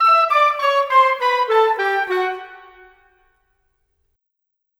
Rock-Pop 20 Clarinet, Flute _ Oboe 02.wav